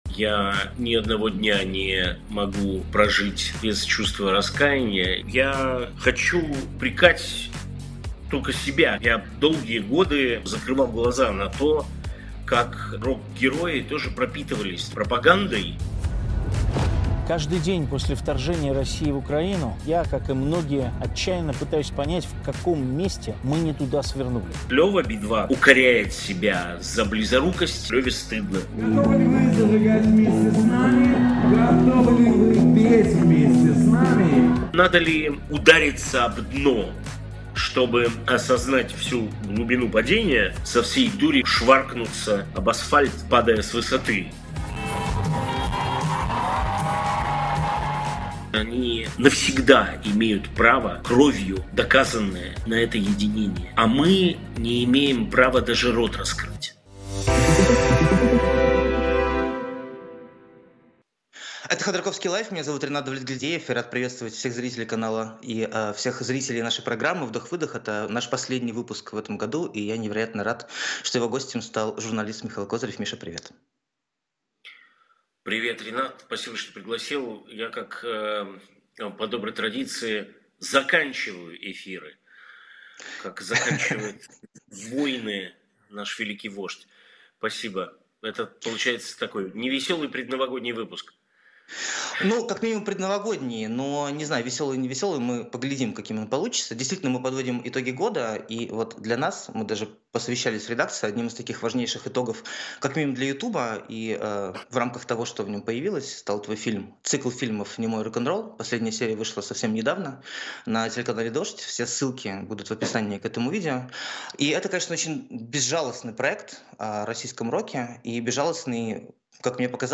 На эти и другие вопросы отвечает журналист и телеведущий Михаил Козырев .